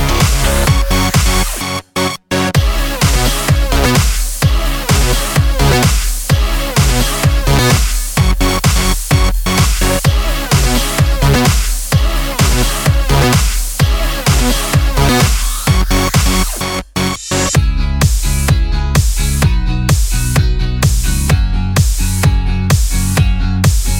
For Duet No Backing Vocals Comedy/Novelty 3:28 Buy £1.50